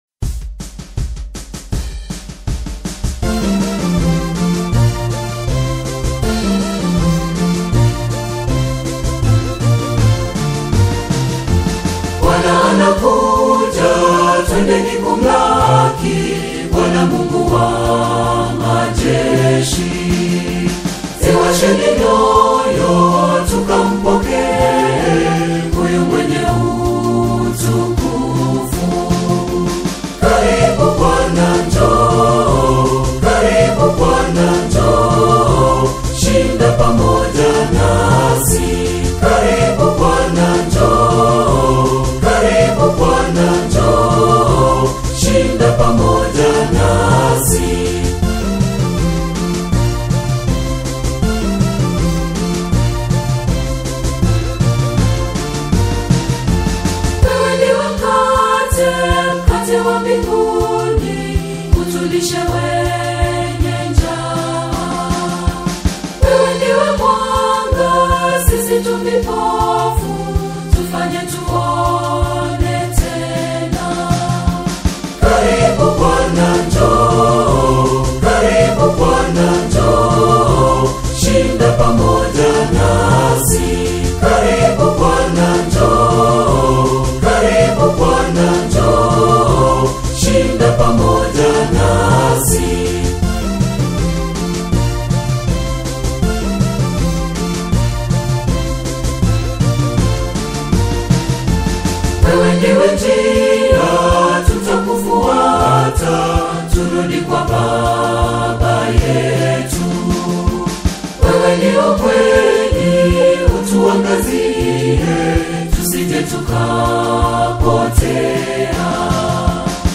a Catholic choir based in Kenya
bongo flava